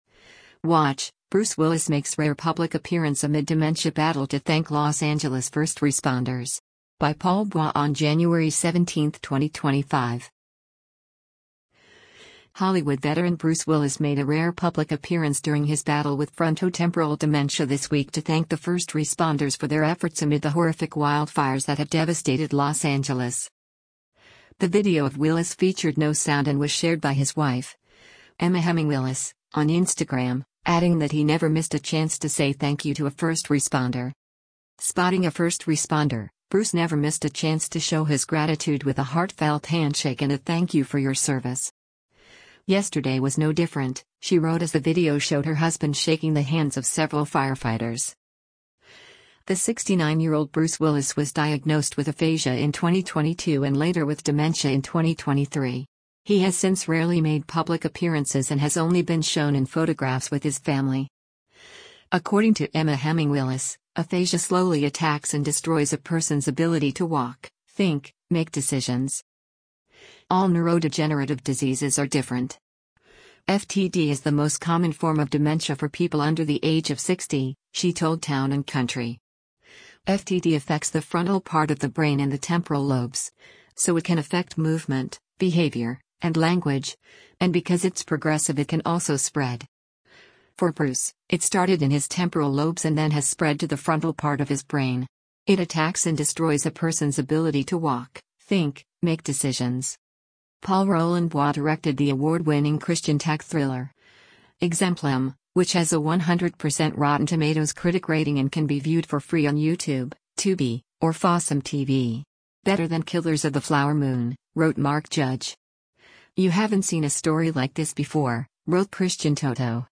The video of Willis featured no sound and was shared by his wife, Emma Heming Willis, on Instagram, adding that he “never missed a chance” to say thank you to a first responder.